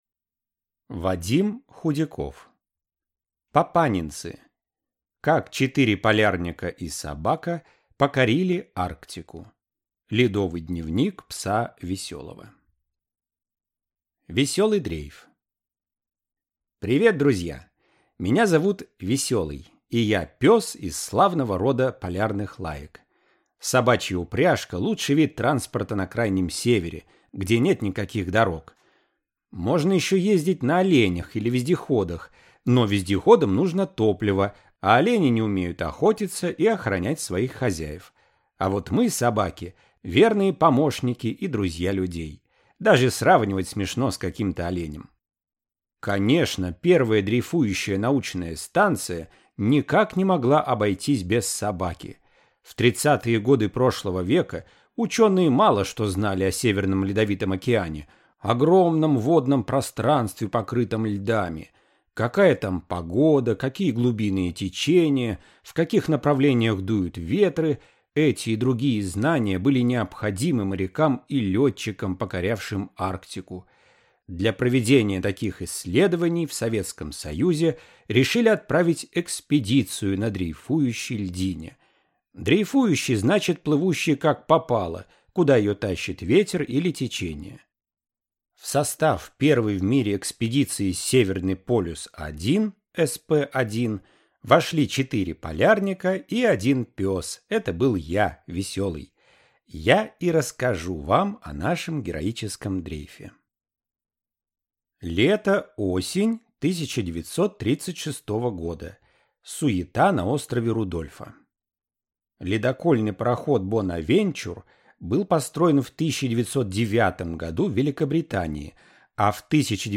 Аудиокнига Папанинцы. Как четыре полярника и собака покорили Арктику | Библиотека аудиокниг